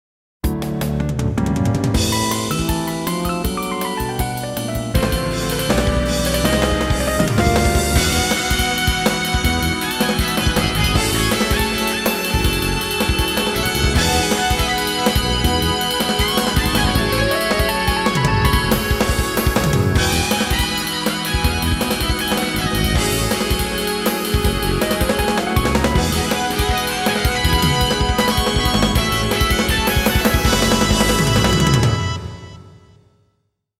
SC-8850で作成した曲の一部抜粋です。
スネア１ とスネア２ とが同時に鳴っているのがわかると思います。
これは、スネアの音に変化を付けるためのものです。
Rideシンバル１ と Rideベル も同様に同じタイミングで入力されています。
特にないんですが、Gtr.2 b は、Gtr.2 a チョーキング時の補助トラックですね。